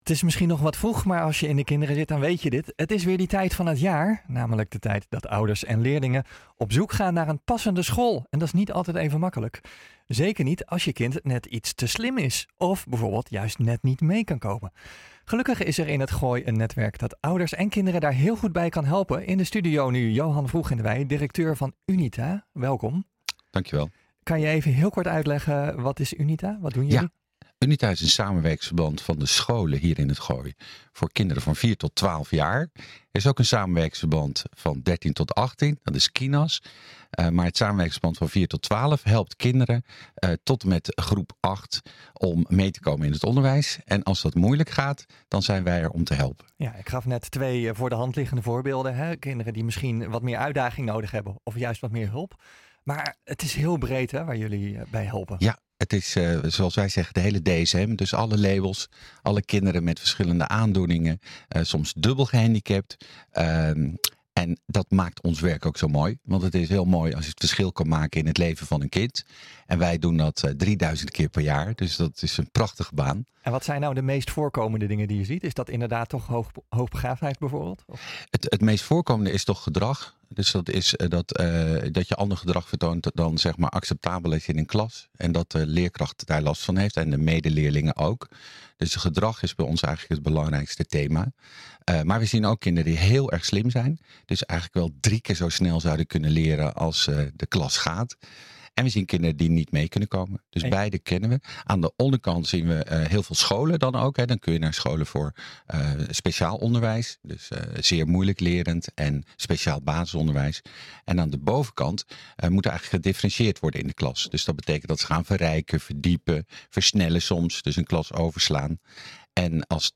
Gelukkig is er in het Gooi een netwerk dat ouders en kinderen daar heel goed bij kan helpen. In de studio nu